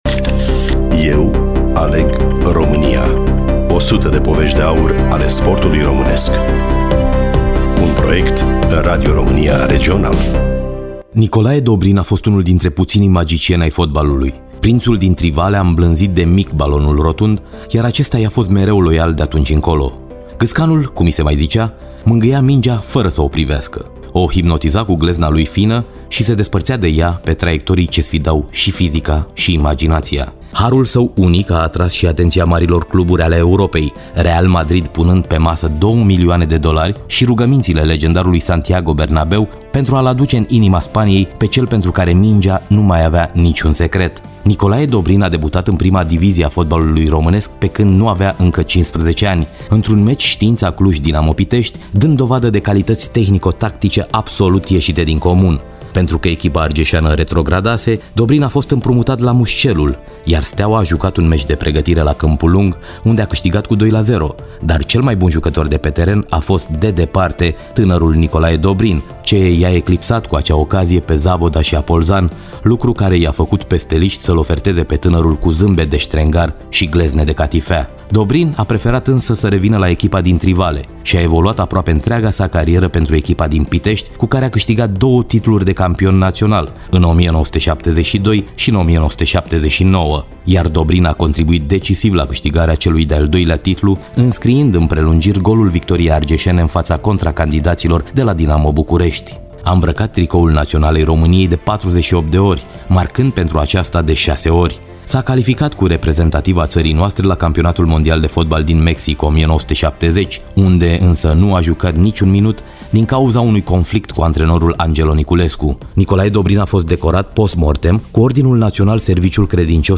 Studioul: Radio România Oltenia Craiova